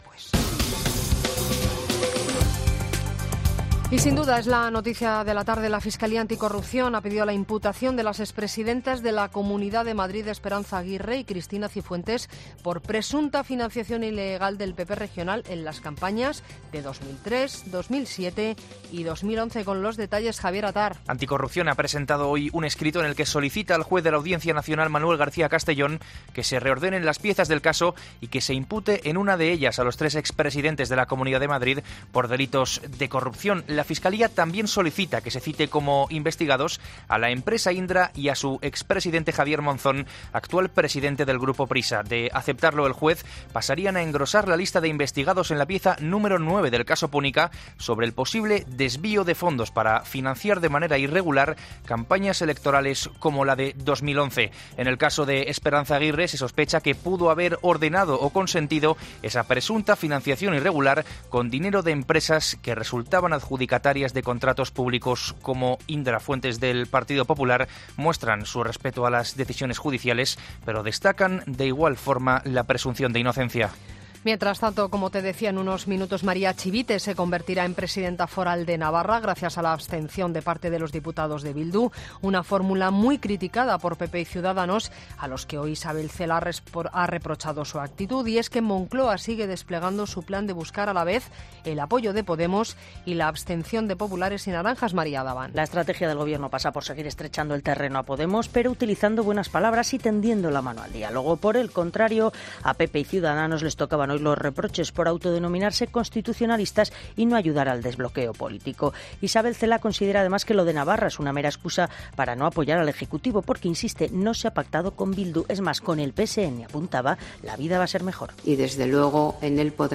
Boletín de noticias de COPE del 2 de agosto de 2019 a las 19.00 horas